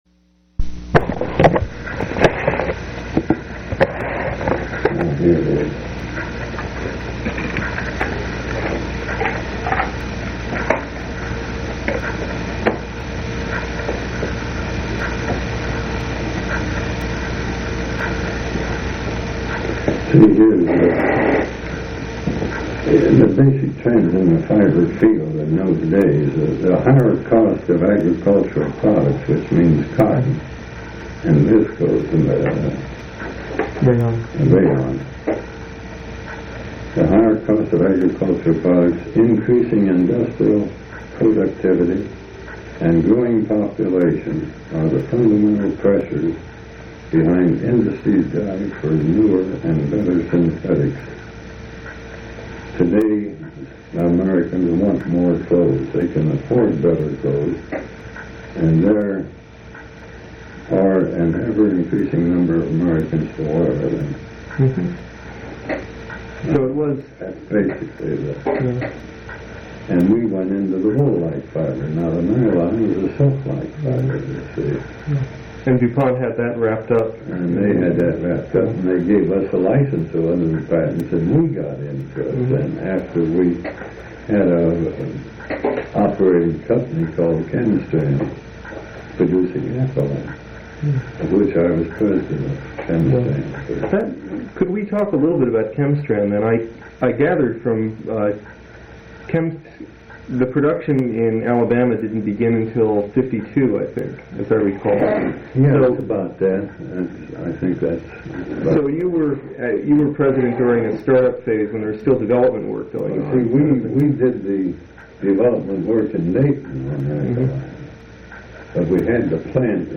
Oral histories
Place of interview Missouri--Clayton